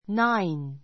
náin ナ イン